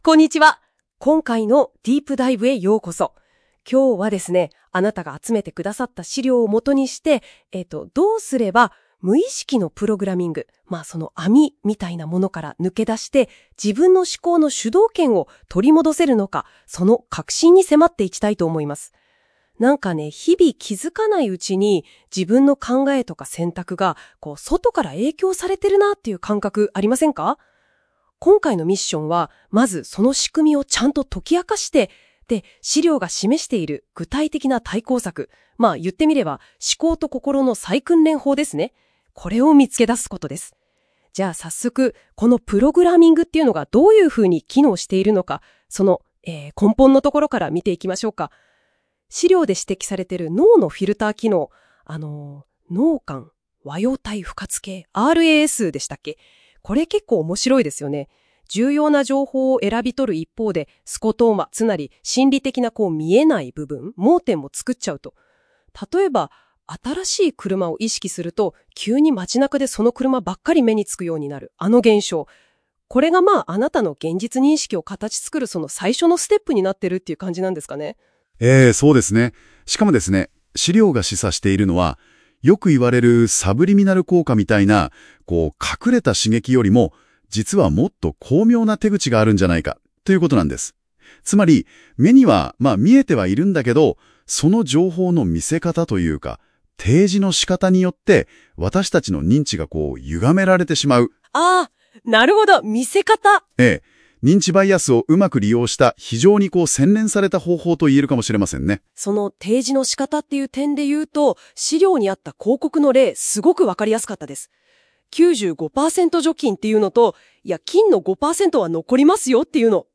【音声解説】無意識のプログラミングから脱却！思考と心の主導権を取り戻す実践ガイド